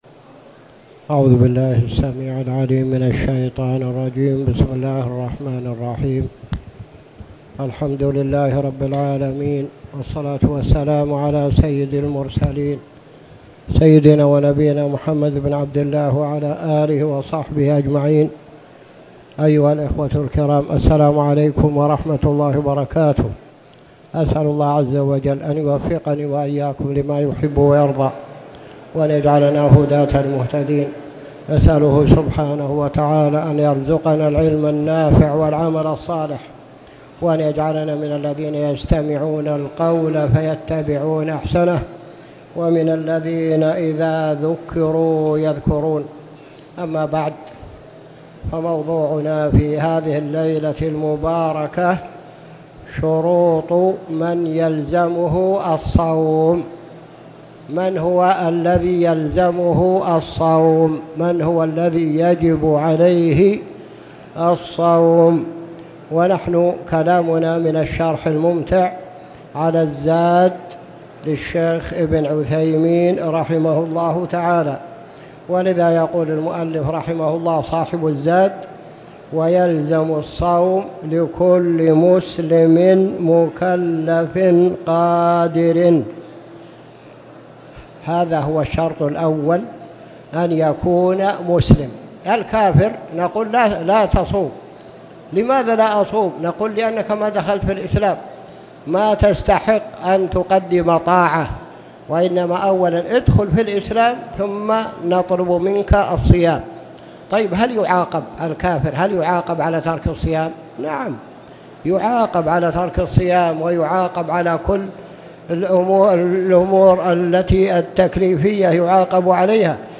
تاريخ النشر ١٩ رجب ١٤٣٨ هـ المكان: المسجد الحرام الشيخ